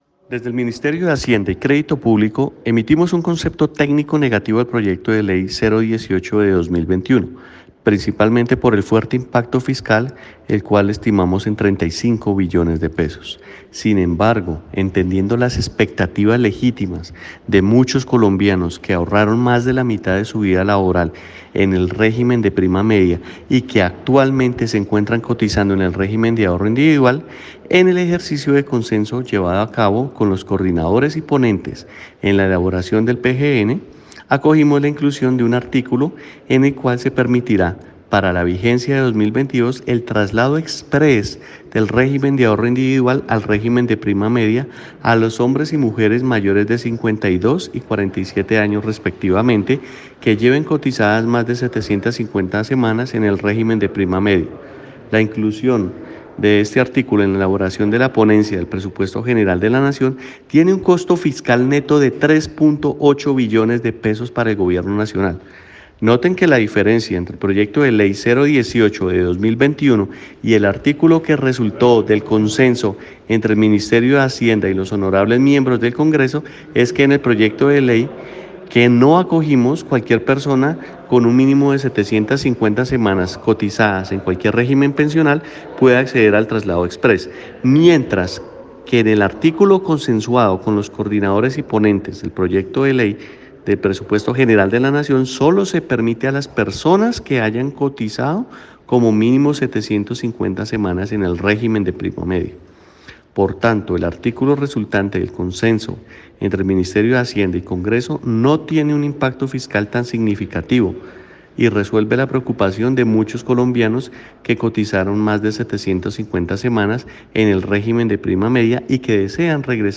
Declaraciones del viceministro Técnico, Jesús Bejarano, sobre el traslado de pensiones